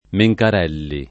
[ me j kar $ lli ]